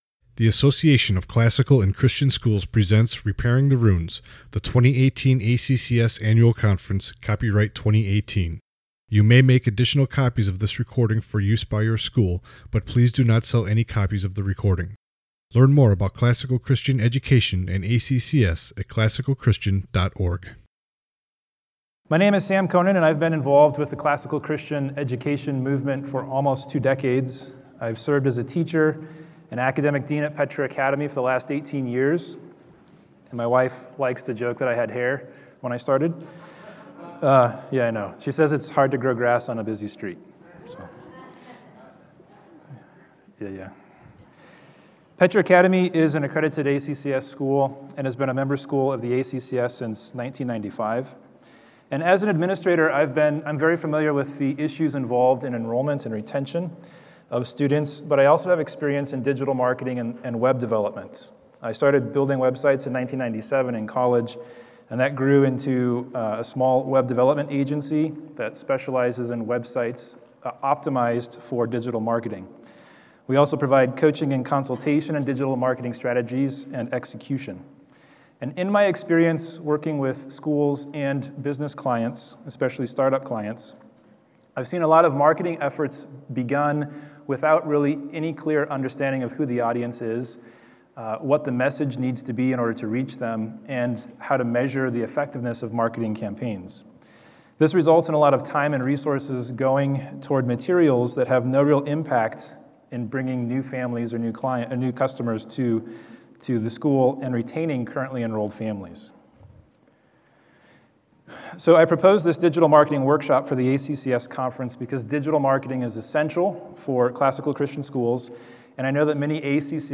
2018 Leaders Day Talk | 33:02 | Fundraising & Development, Marketing & Growth